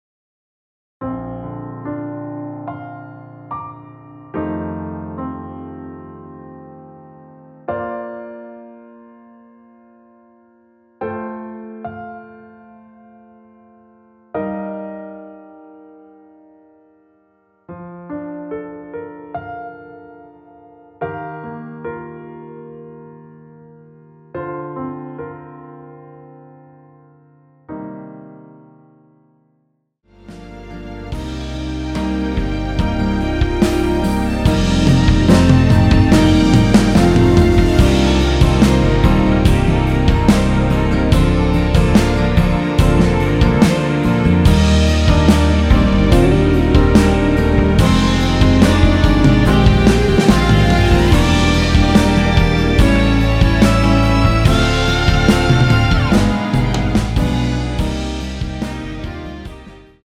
원키에서 (-2)내린 MR 입니다.
노래가 바로 시작 하는 곡이라 전주 만들어 놓았습니다.
Bb
앞부분30초, 뒷부분30초씩 편집해서 올려 드리고 있습니다.